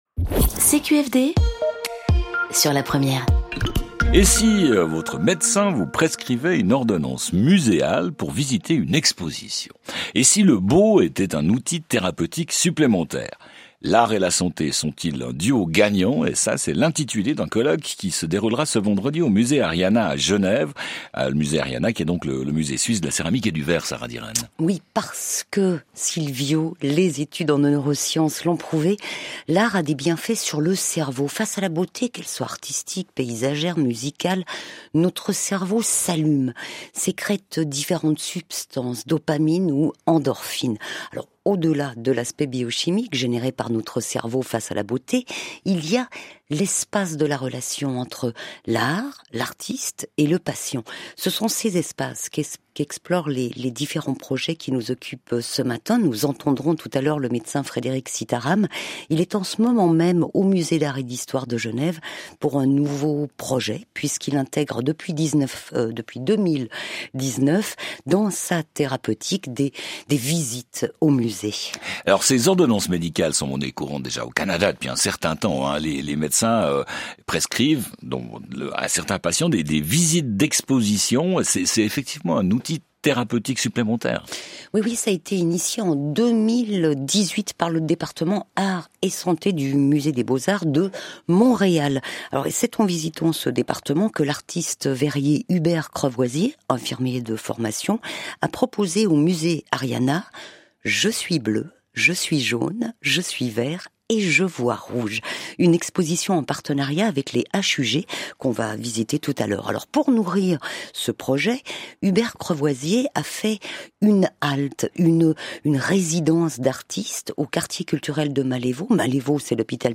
Reportage au Musée Ariana